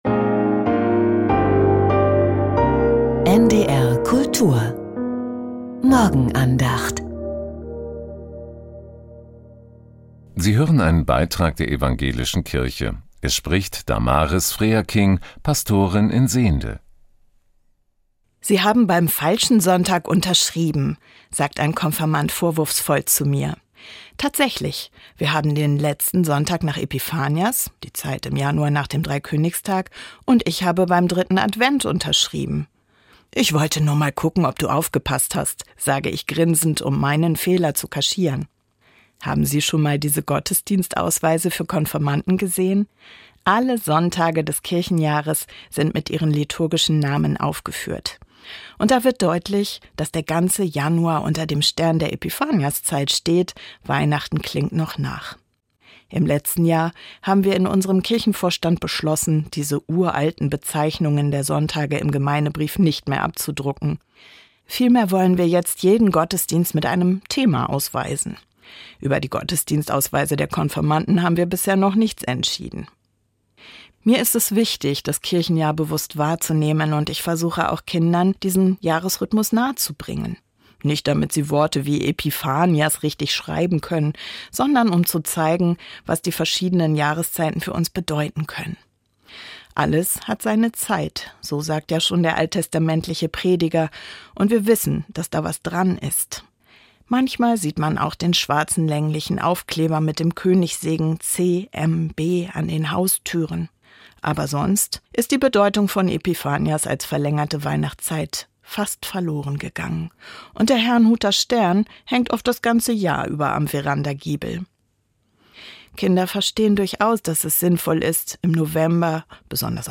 Herrnhuter Stern ~ Die Morgenandacht bei NDR Kultur Podcast